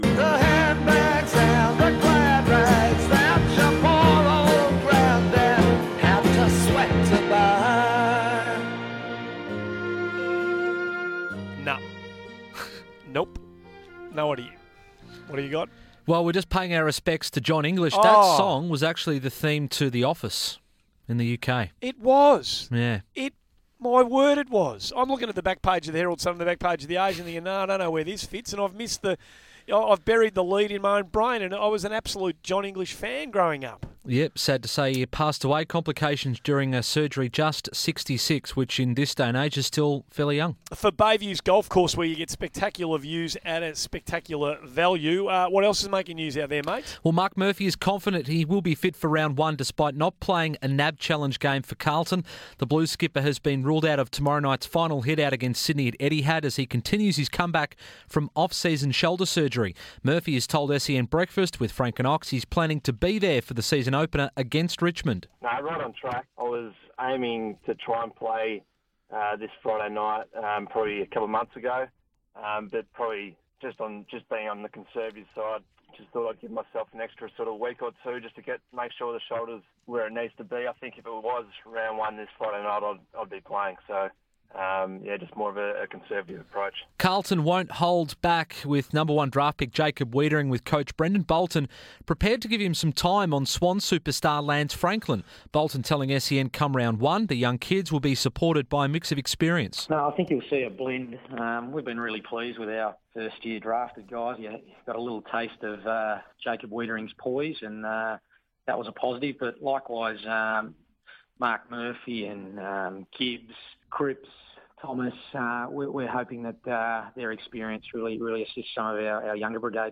2pm Sport Update